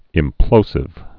(ĭm-plōsĭv)